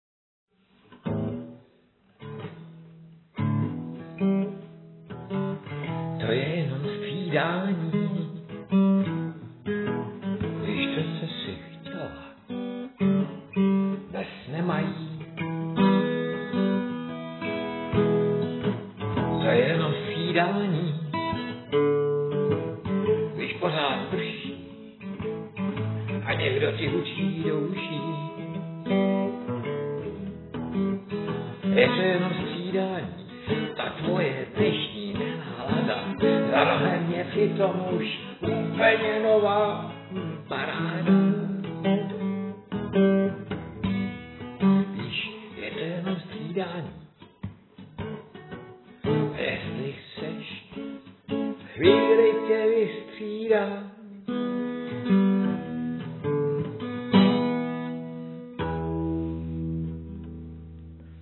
Anotace: Byl jsem zvědavý jak to bude znít, když to nazpívám jako blues se svou starou akustikou.